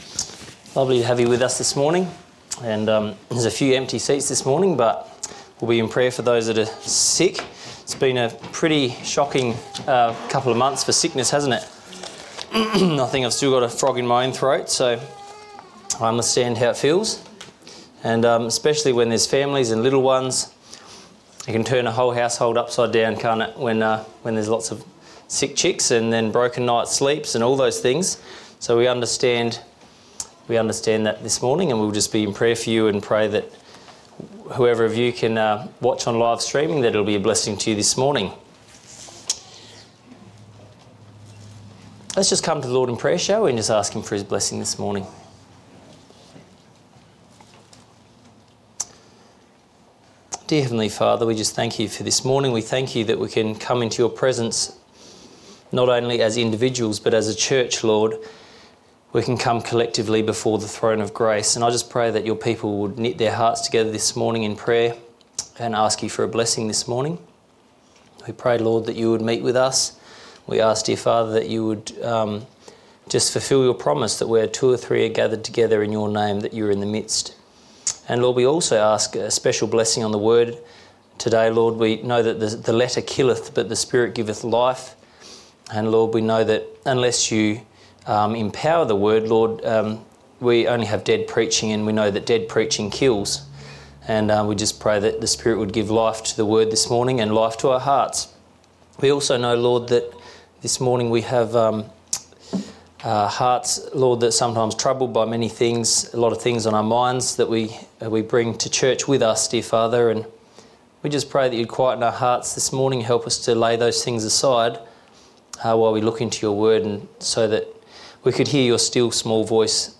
Sermons – Page 4 – Golden West Baptist Church